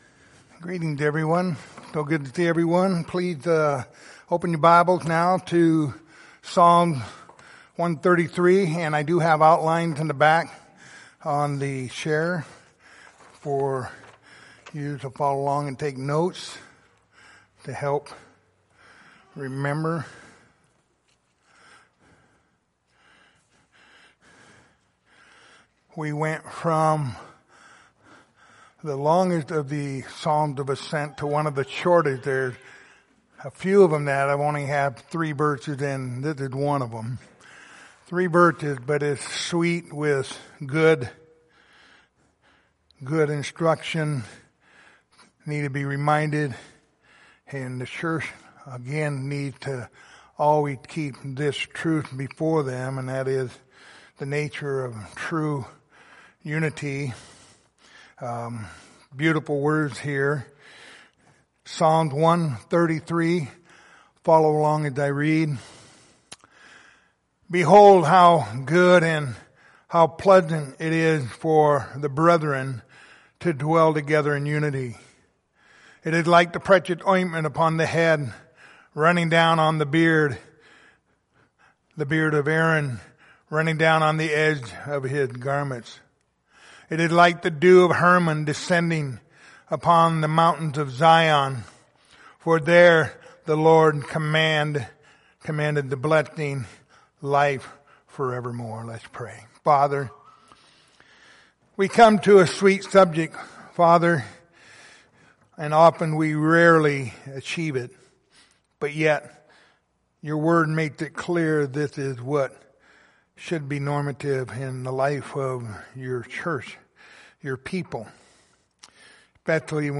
Passage: Psalm 133:1-3 Service Type: Sunday Evening